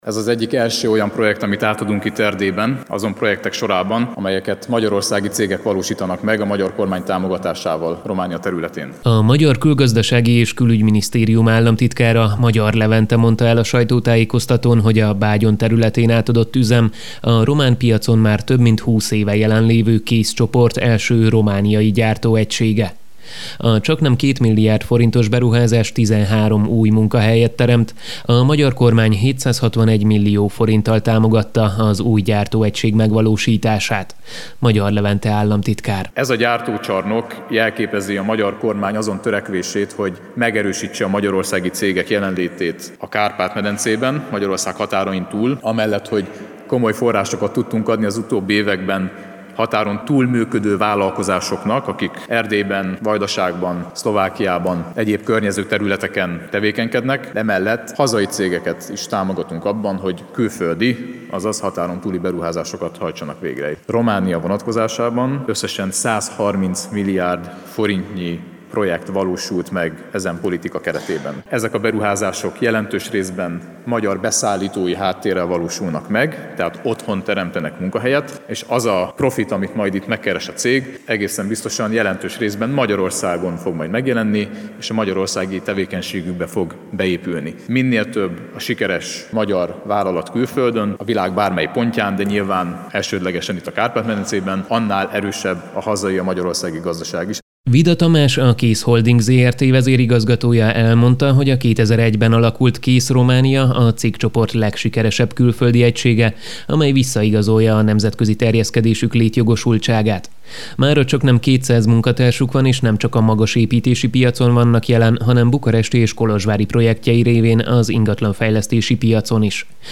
Az új gyártóegység átadásán tartott sajtótájékoztatóról